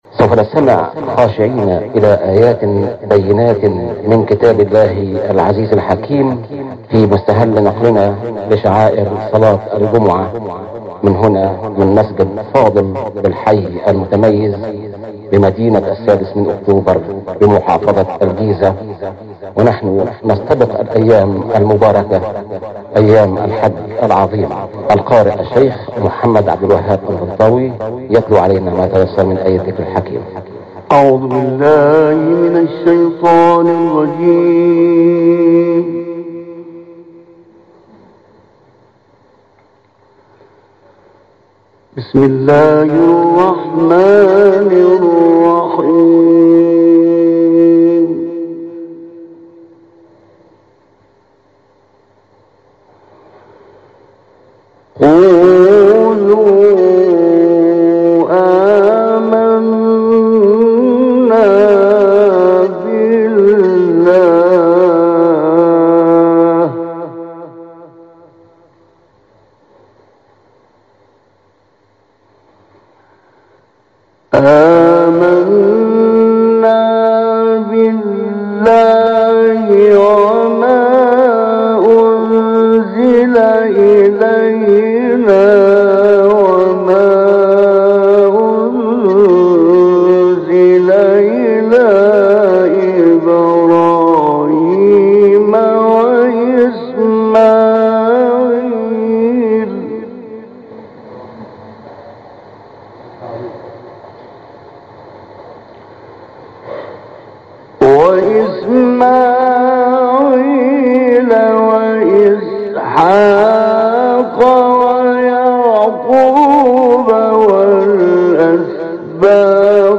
تلاوت آیاتی از سوره بقره
روز گذشته 22 مردادماه در مراسم نماز جمعه اجرا شده است.